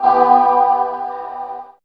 64 GUIT 4 -L.wav